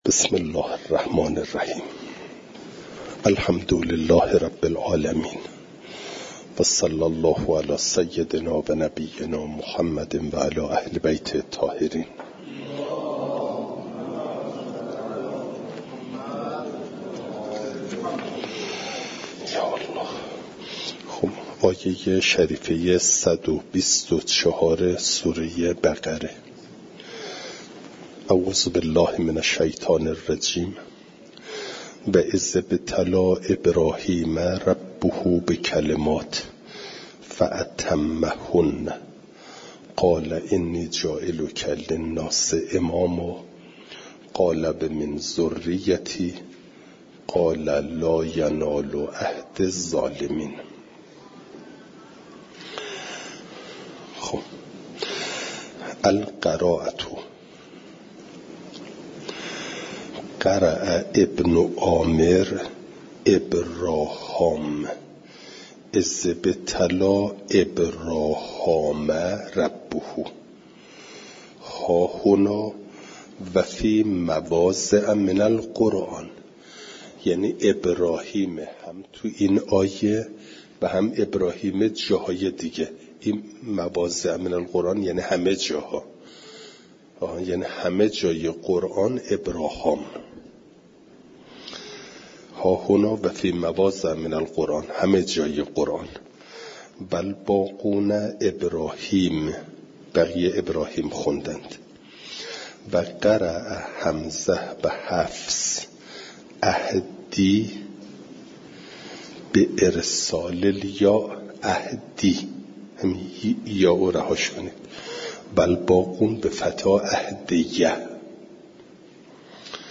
فایل صوتی جلسه صد و بیست و سوم درس تفسیر مجمع البیان